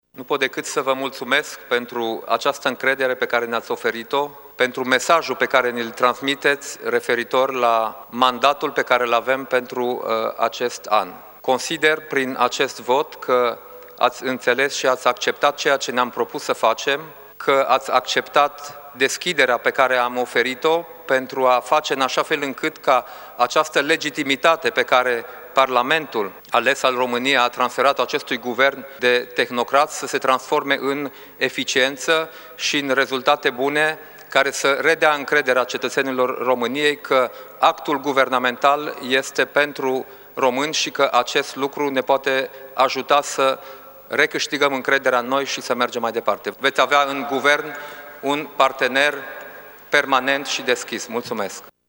Dacian Cioloș a mulțumit pentru încrederea acordată: